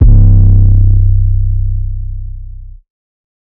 808 [Grey].wav